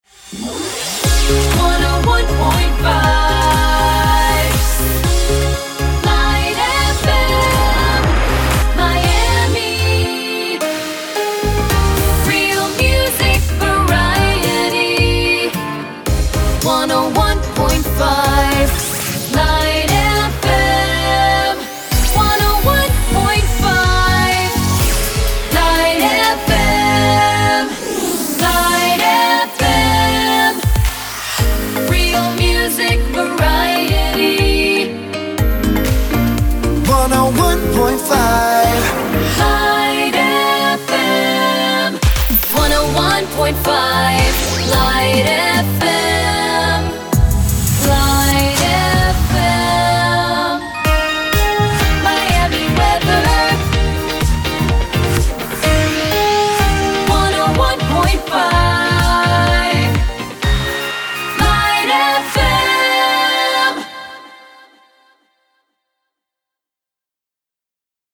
Multiple mixes
Instrumentals
Vocals